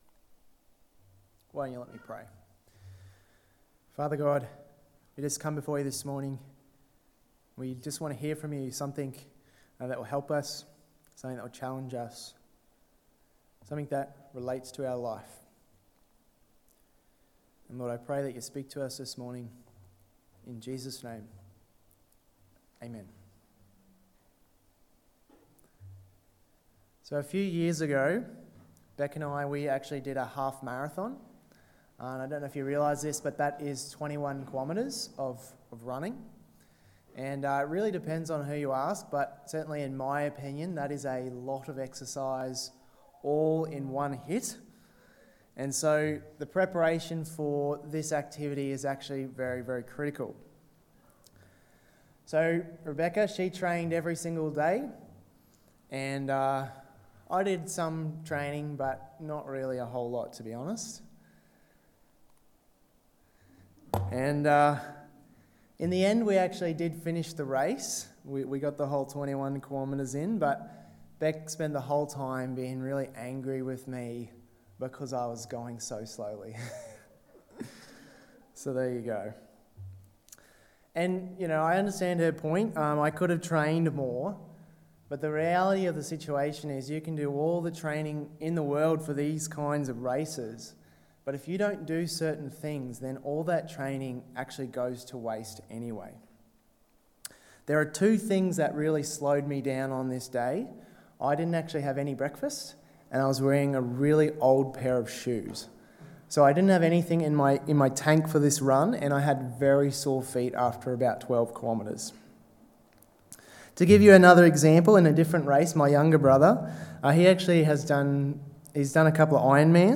Being Prepared: A Warning, a Knife, and a Kiss (Exodus 4:21-31 Sermon) 18/06/2023